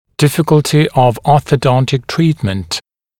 [‘dɪfɪk(ə)ltɪ əv ˌɔːθə’dɔntɪk ‘triːtmənt][‘дифик(э)лти ов ˌо:сэ’донтик ‘три:тмэнт]сложность ортодонтического лечения